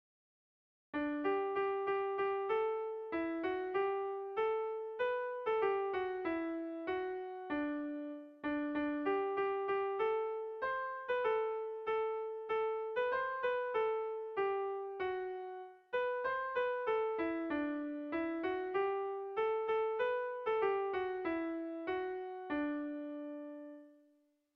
Seiko handia (hg) / Hiru puntuko handia (ip)
A1BA2